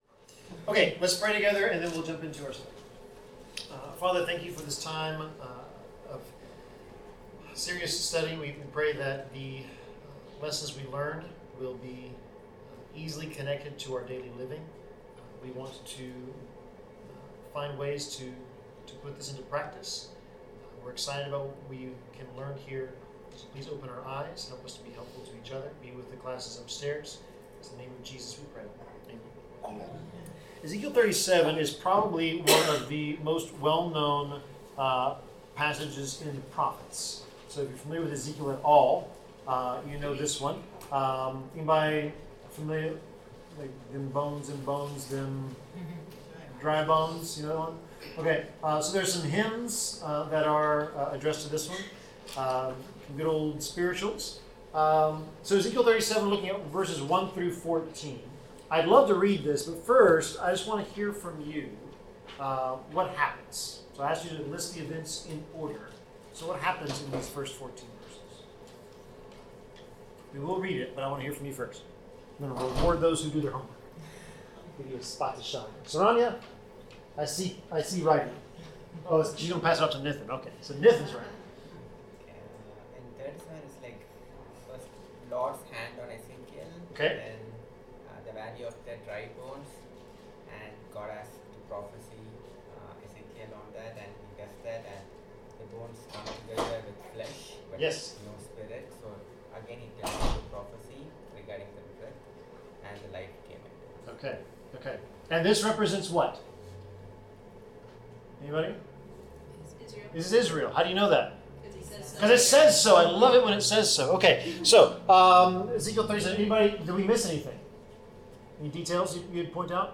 Bible class: Ezekiel 37-39
Passage: Ezekiel 37-39 Service Type: Bible Class